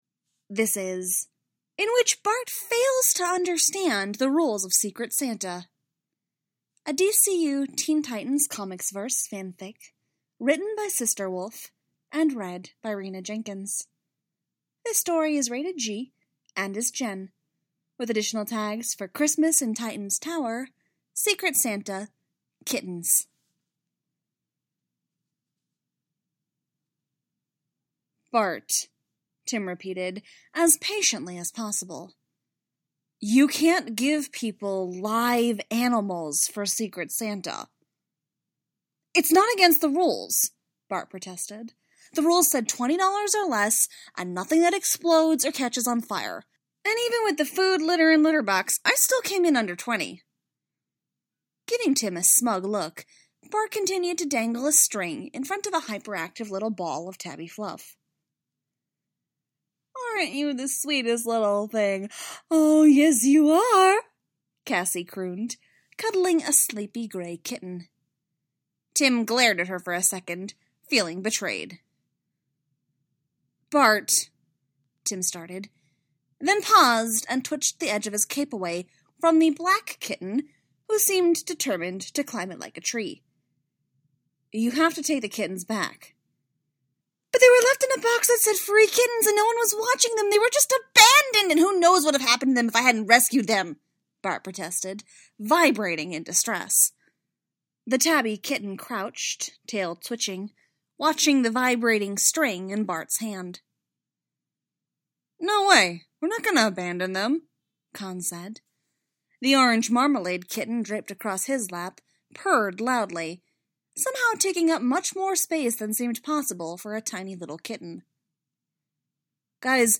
with music download mp3: here (r-click or press, and 'save link') [3 MB, 00:03:29]